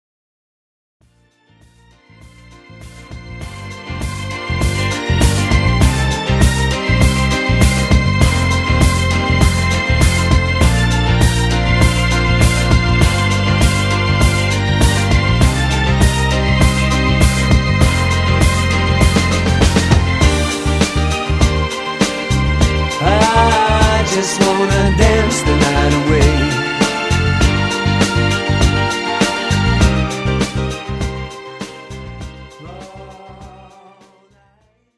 This is an instrumental backing track cover.
• Key – B♭
• With Backing Vocals
• With Fade